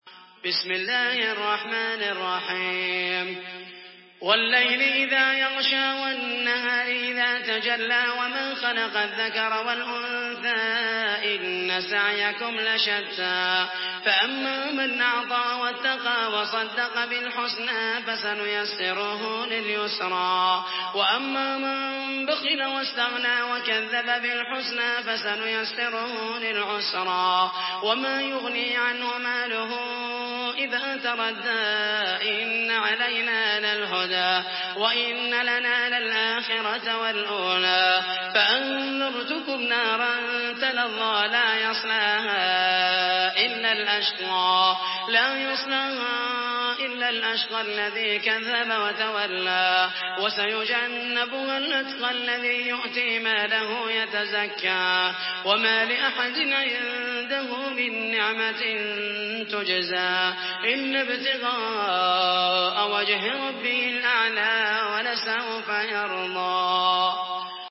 Surah আল-লাইল MP3 by Muhammed al Mohaisany in Hafs An Asim narration.